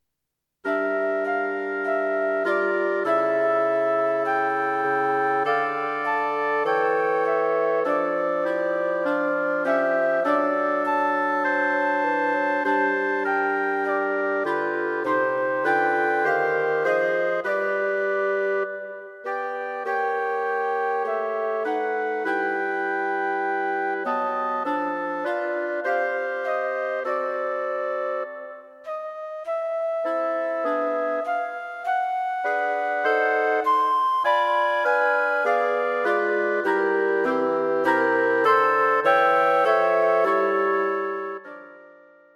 Wind Quartet
A nice, easy composition.